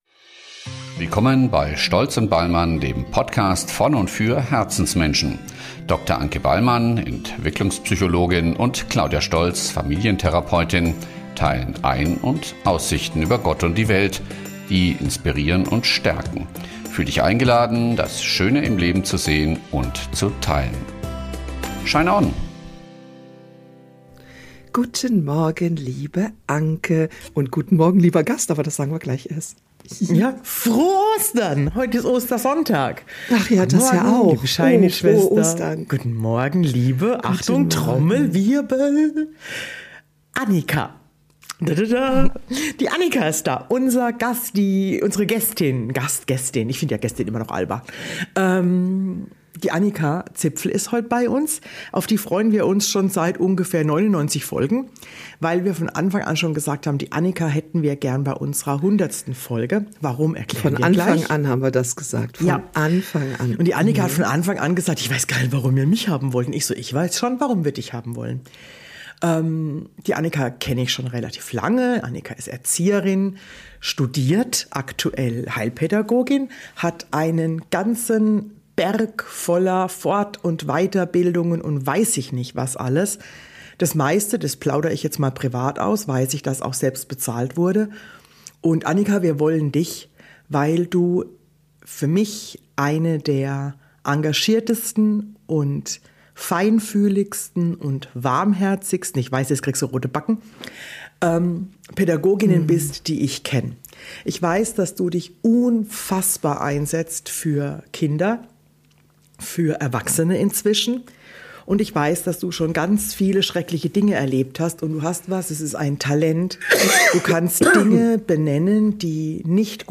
Taraaa und Törööö – nun sind wir dreistellig und wie bei jedem Jubiläum zu dritt.